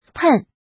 pèn
pen4.mp3